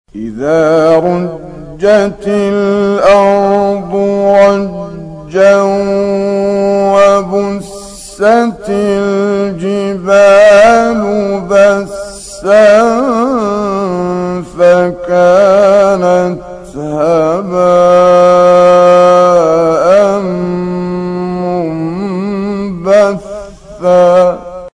15 فراز از «کامل یوسف» در مقام بیات
گروه شبکه اجتماعی: فرازهای صوتی از کامل یوسف البهتیمی که در مقام بیات اجرا شده است، می‌شنوید.